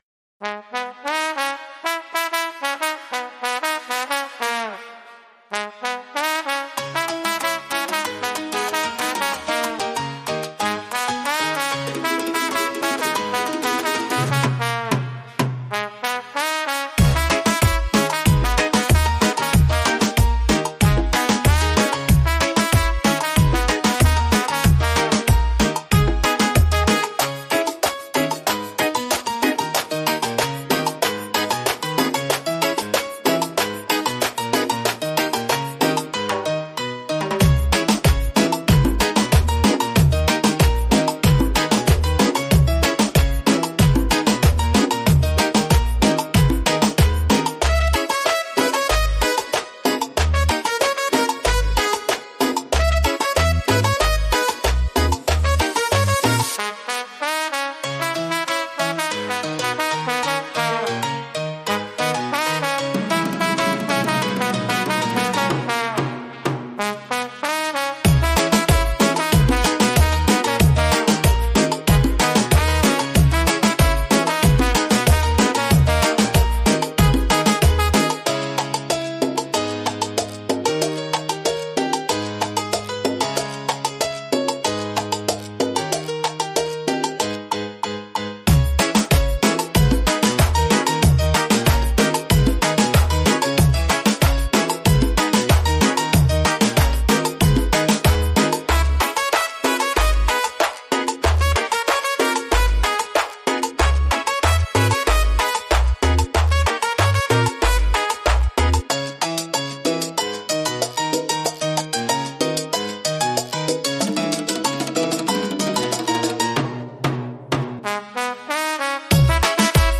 Beat Reggaeton Instrumental
Acapella e Cori Reggaeton Inclusi
• Mix e mastering di qualità studio
G#m